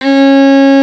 STR FIDDLE02.wav